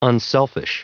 Prononciation du mot unselfish en anglais (fichier audio)
Prononciation du mot : unselfish